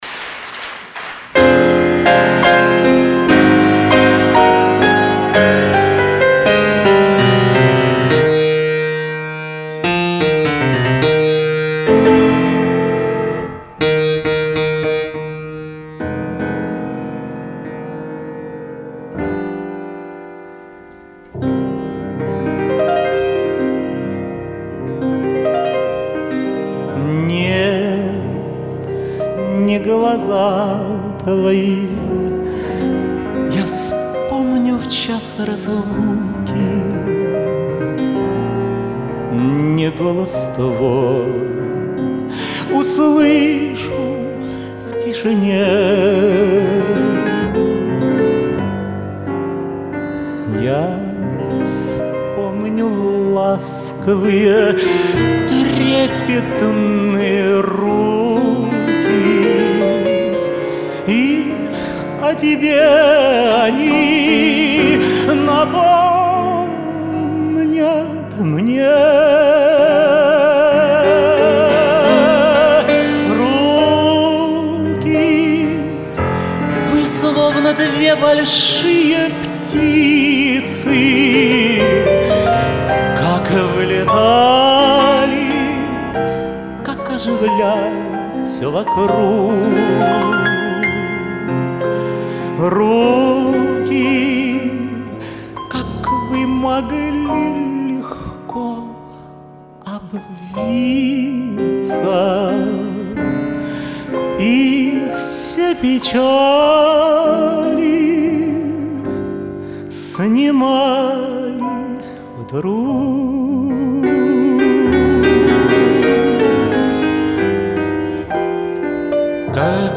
Добавлю в мужском исполнении этот романс